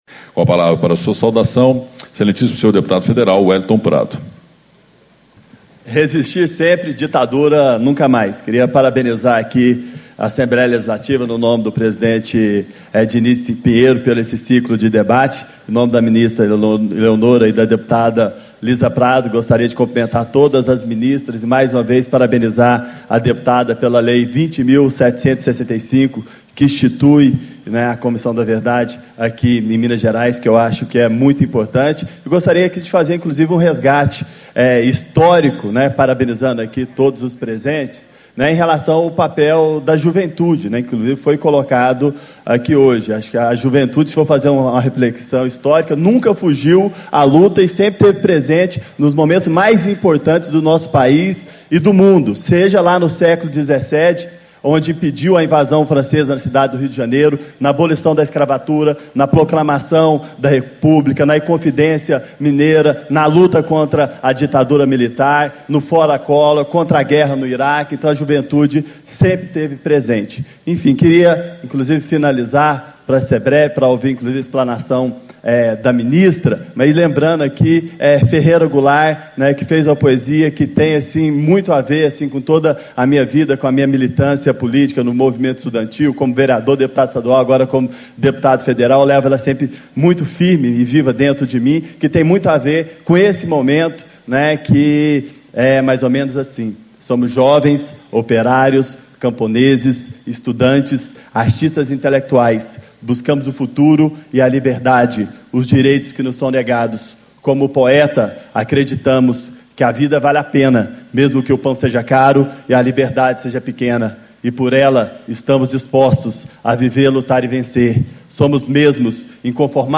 Abertura - Deputado Federal Weliton Prado, PT
Discursos e Palestras